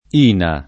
vai all'elenco alfabetico delle voci ingrandisci il carattere 100% rimpicciolisci il carattere stampa invia tramite posta elettronica codividi su Facebook INA [ & na ] n. pr. m. — sigla di Istituto Nazionale delle Assicurazioni